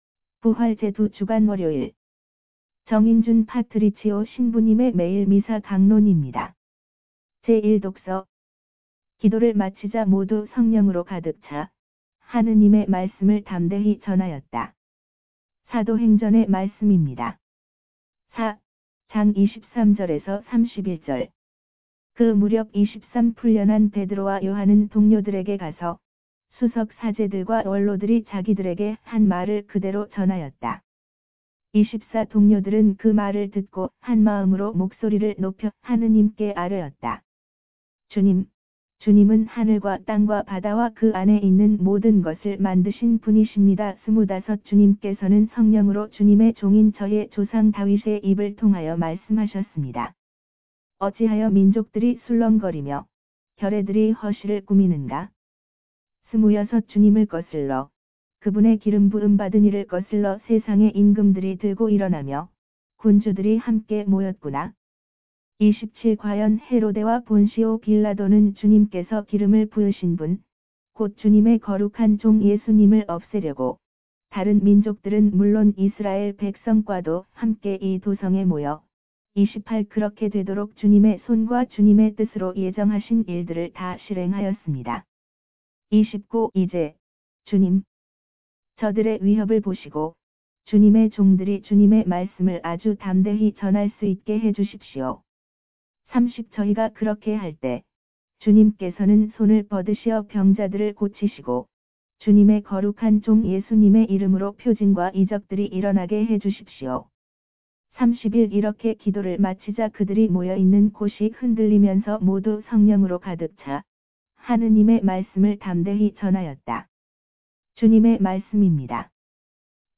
강론